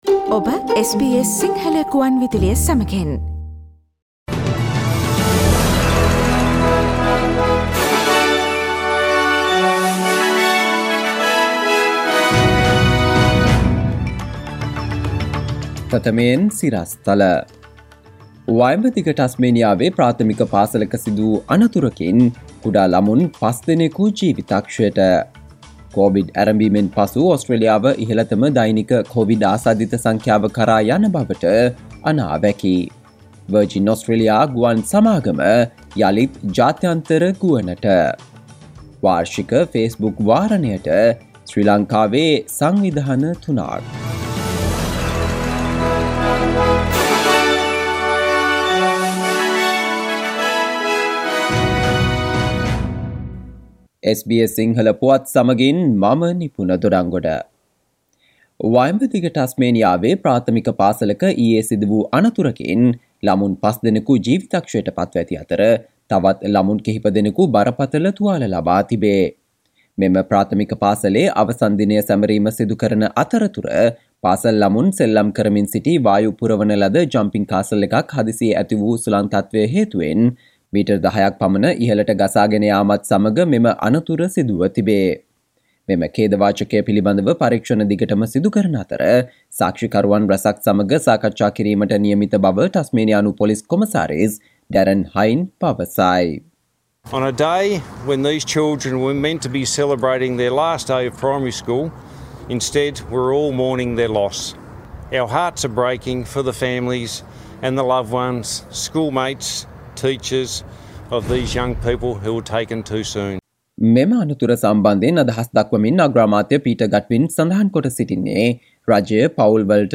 සවන්දෙන්න 2021 දෙසැම්බර් 17 වන සිකුරාදා SBS සිංහල ගුවන්විදුලියේ ප්‍රවෘත්ති ප්‍රකාශයට...